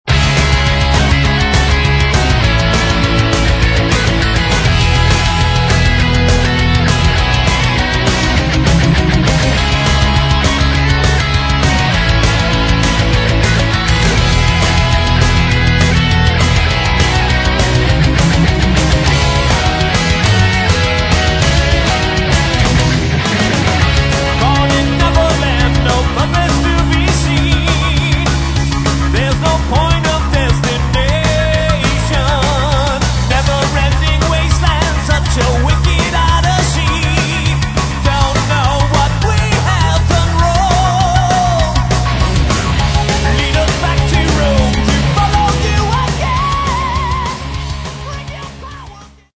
гитары
бас
ударные
клавишные